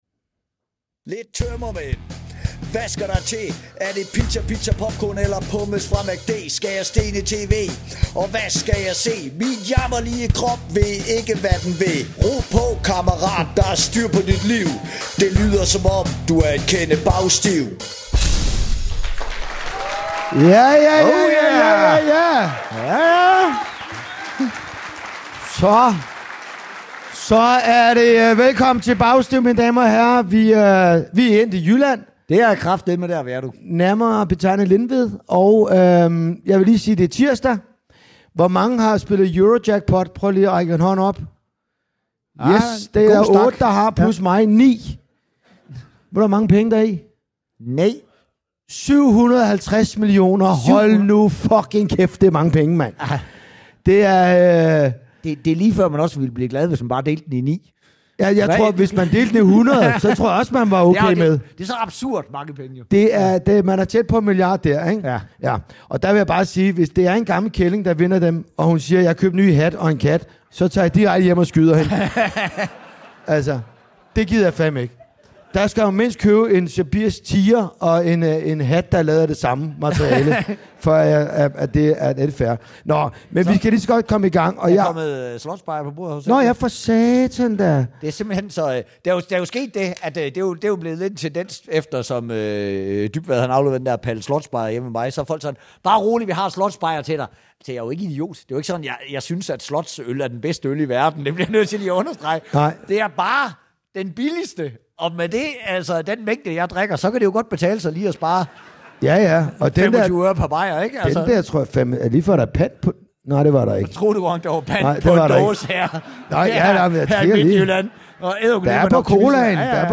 Showet er optaget live i Lindved Sognegård.